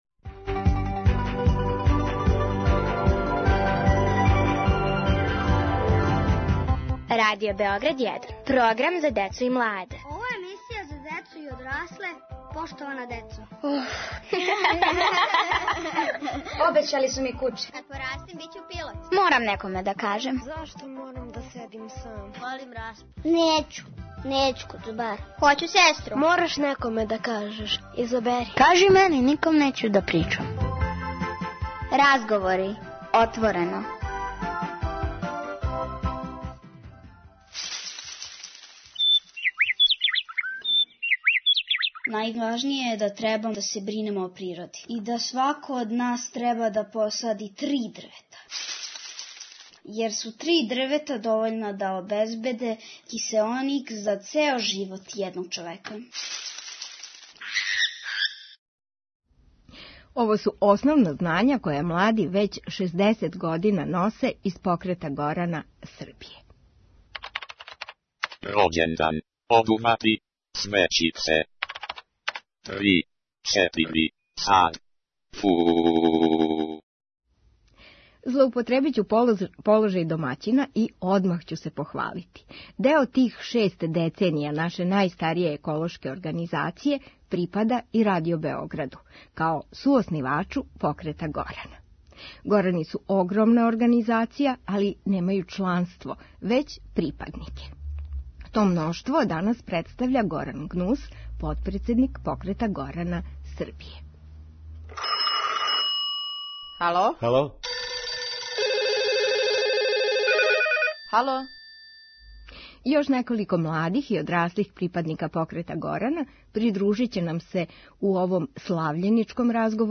Поводом 60. рођендана Покрета горана Србије поштовани млади и поштовани одрасли горани разговарају о очувању природе, пошумљавању, лековитом биљу и заједничком учењу.